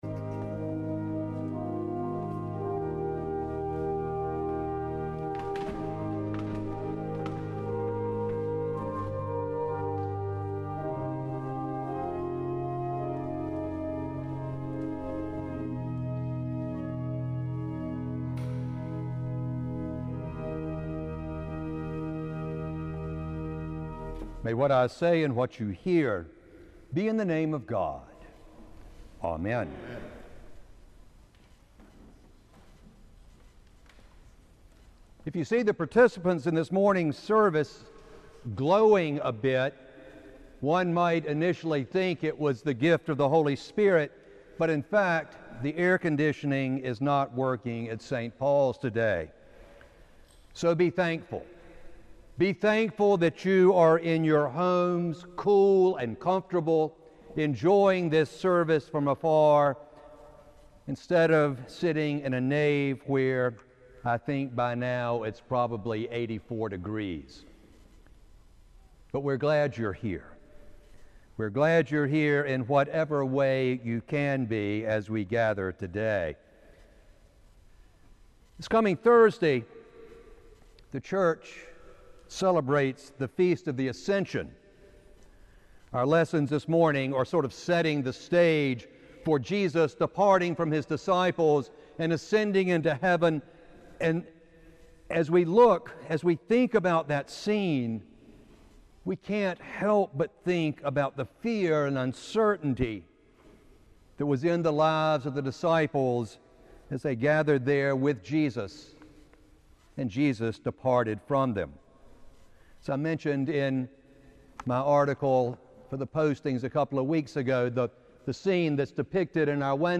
Audio Sermon only
This service was livestreamed to an on-line audience only.